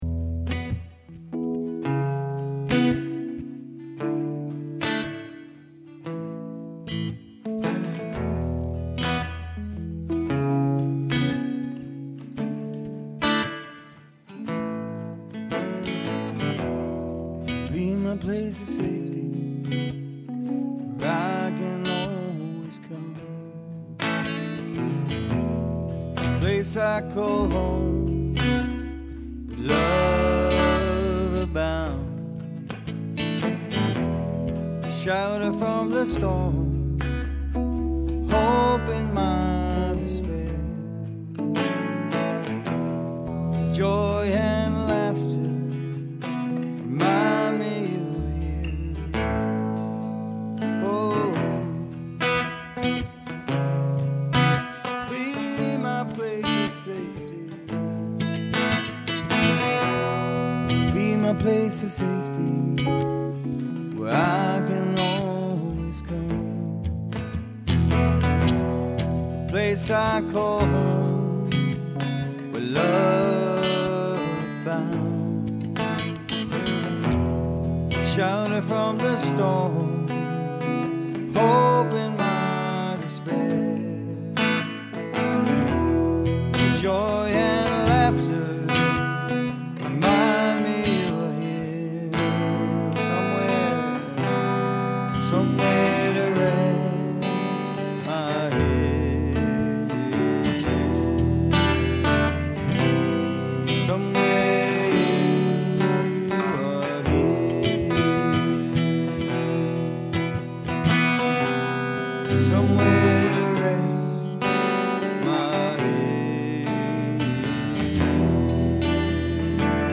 Once we got the music out everyone joined in.
shaker